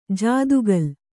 ♪ jādugal